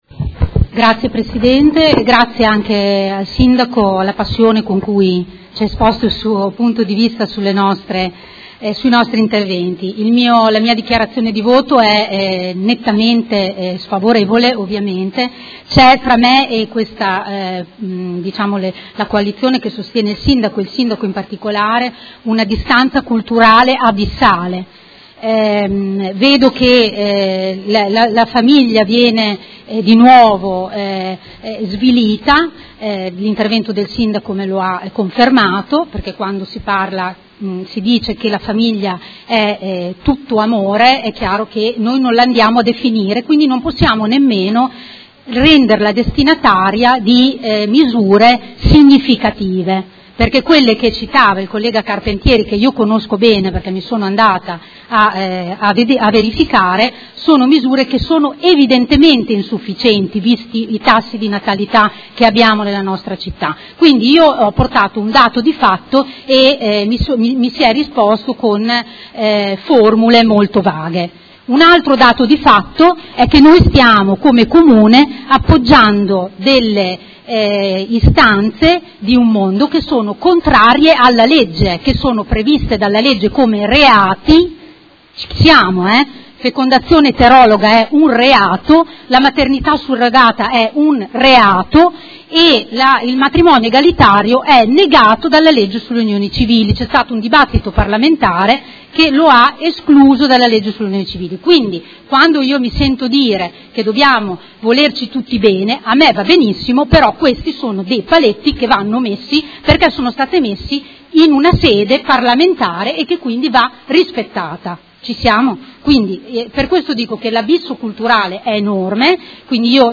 Seduta del 20/06/2019. Dichiarazione di voto su proposta di deliberazione: Indirizzi Generali di Governo 2019-2024 - Discussione e votazione